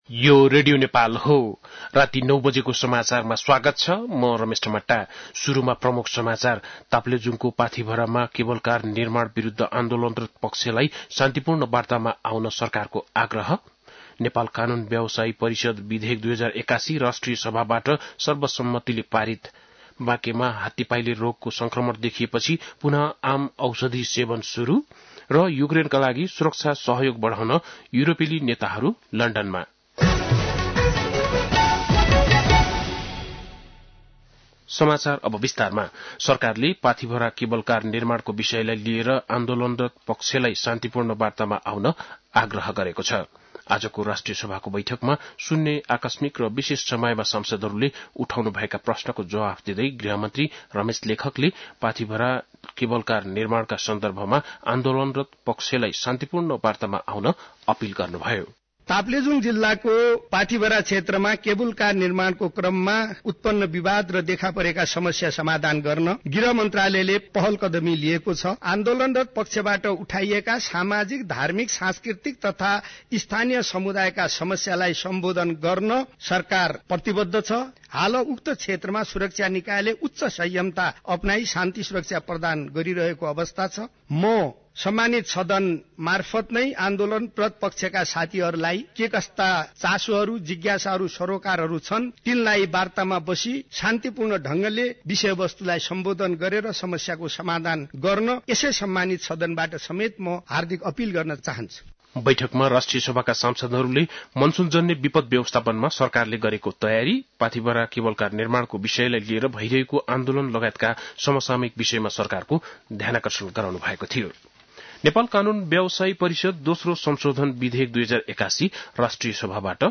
बेलुकी ९ बजेको नेपाली समाचार : १९ फागुन , २०८१
9-pm-nepali-news.mp3